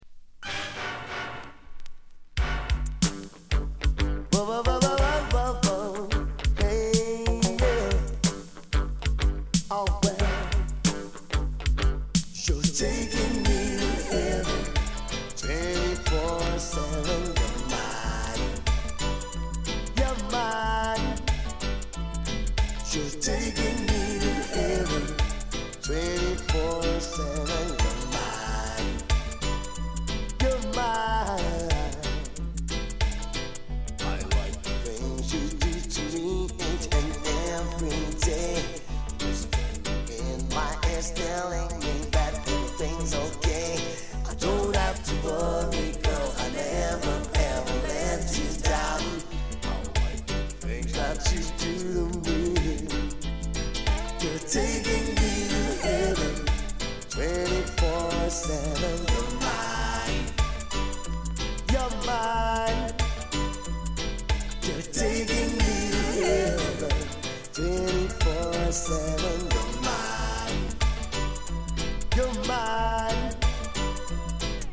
R＆B～LOVERS
音に影響ない 軽い反り。